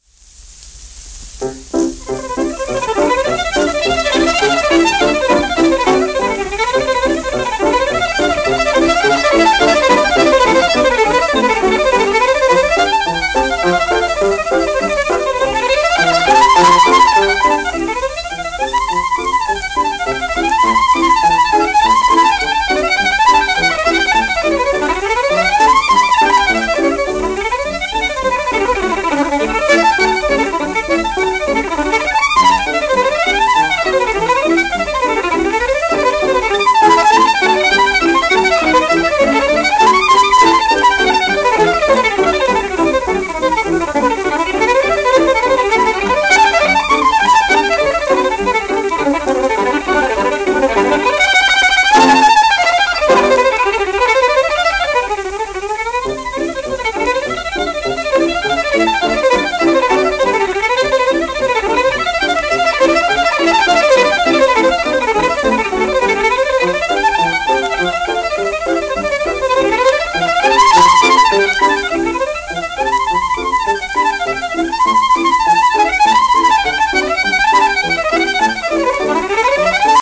ルッジェーロ・リッチ(Vn:1918-2012)
w/L.パーシンガー(p)
SP盤ですが電気再生用です。